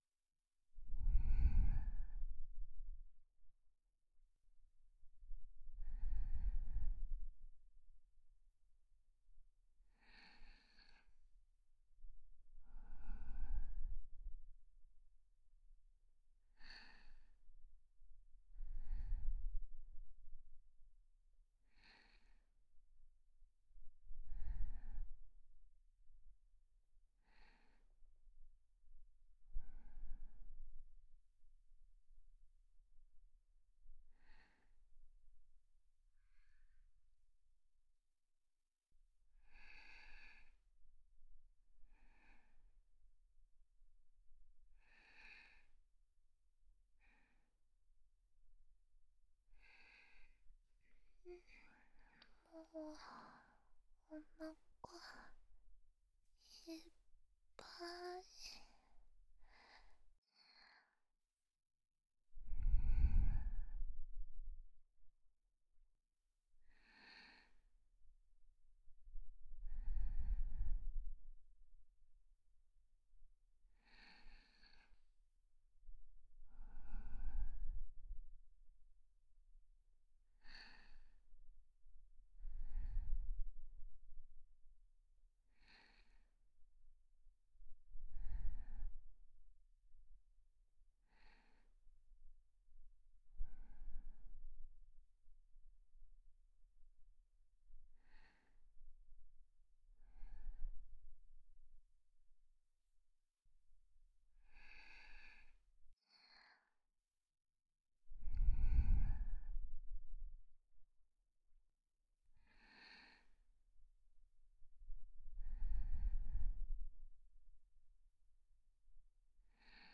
ASMR
06【mp3左耳】寝息ロングループ用.mp3